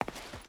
Stone Walk 3.wav